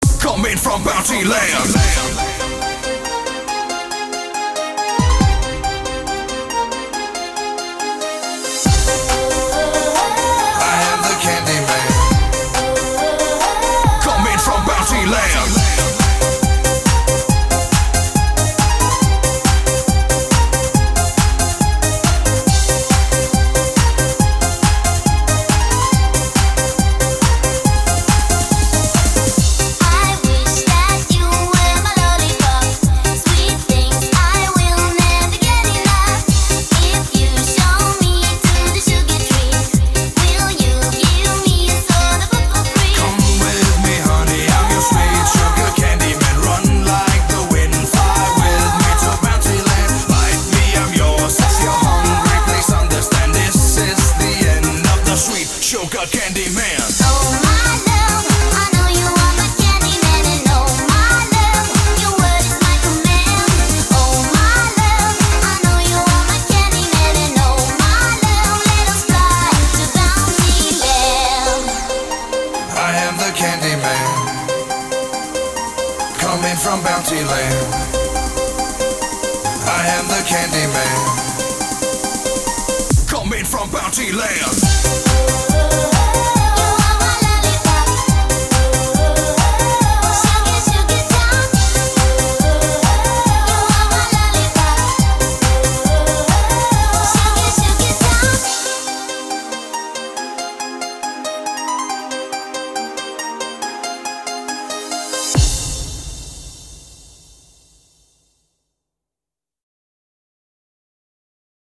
BPM139
Audio QualityPerfect (Low Quality)